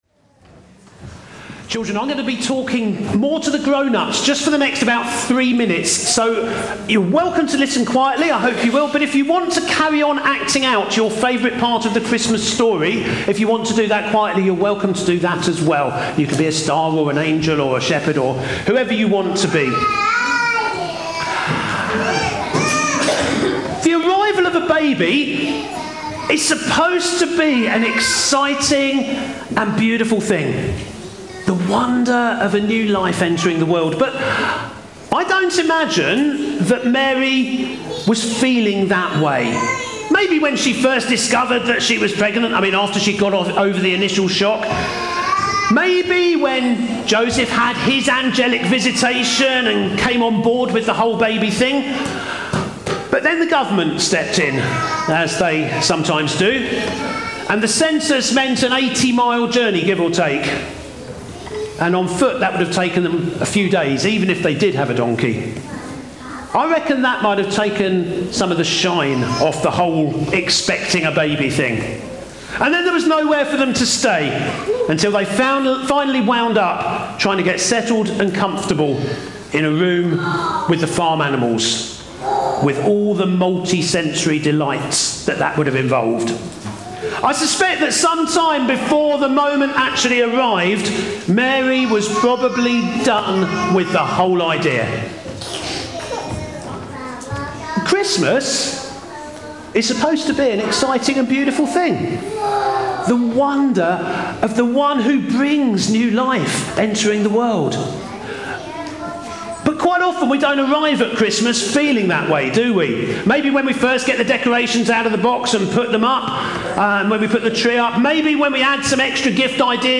From Series: "Stand Alone Sermons 2025"